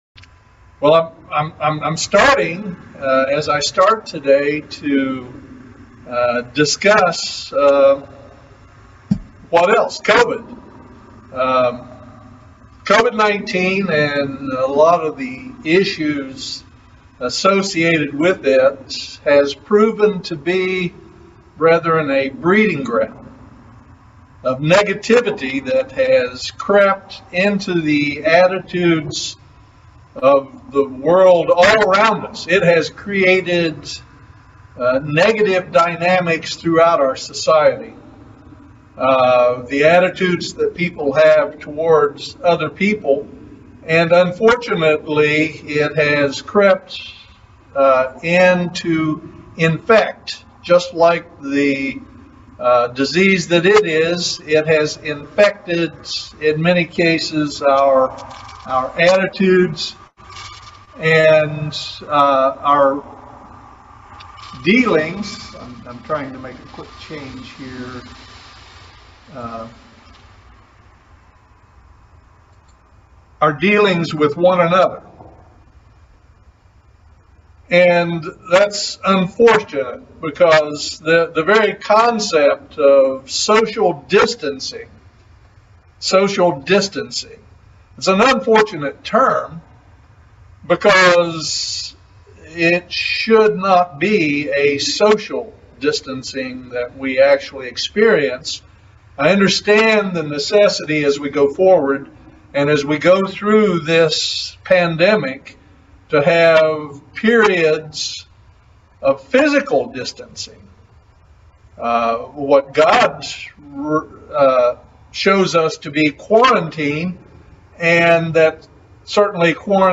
Join us for this excellent video sermon on the subject of modern day differences that are dividing people today.